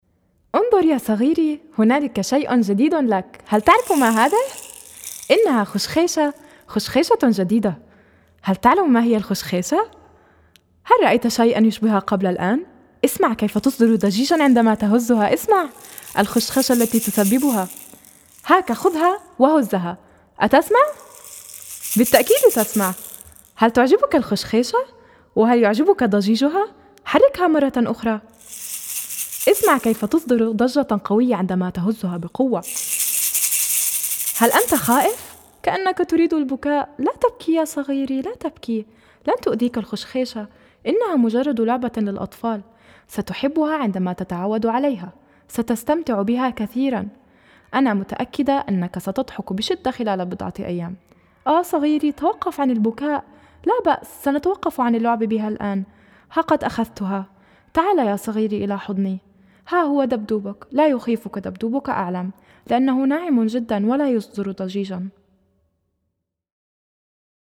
Sensibilisation des oreilles de bébé grâce à des séquences de bavardage aux longues phrases pour que bébé capte la musicalité et le rythme de la langue arabe.